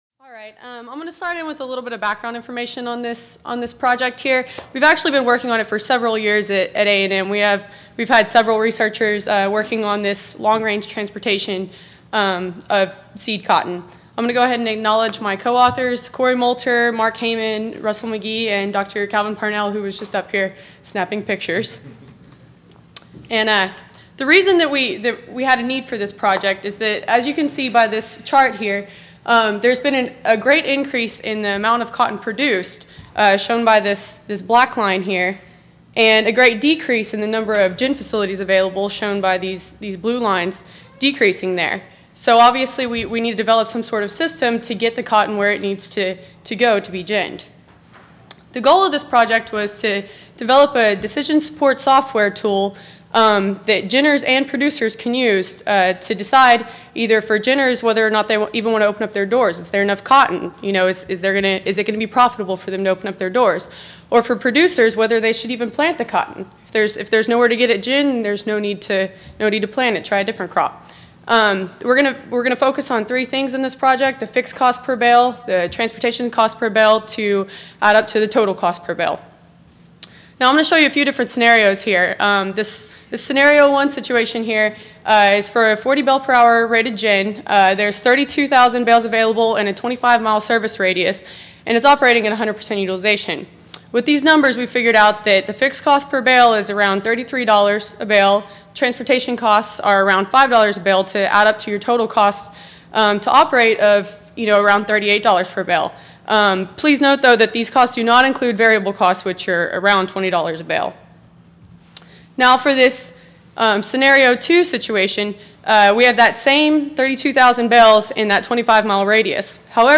Joint Session: Cotton Engineering-Systems and Ginning - Morning Session
Audio File Recorded presentation